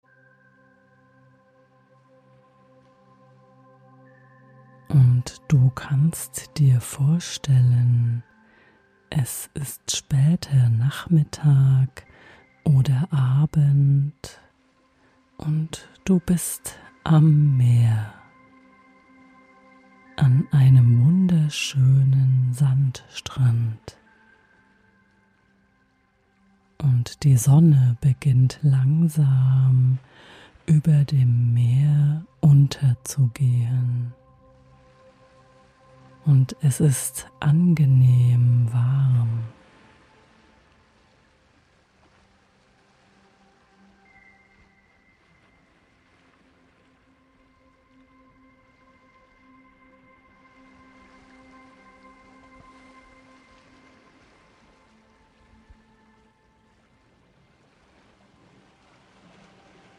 Dies ist eine geführte Fantasiereise, um in Gedanken an einen friedlichen Ort am Meer zu reisen und dort einen sanften Sonnenuntergang zu erleben.
In dieser Reise wirst du eingeladen, an einem lauen Sommerabend am Strand zu sitzen, den Sonnenuntergang zu beobachten und das beruhigende Rauschen der Wellen zu hören.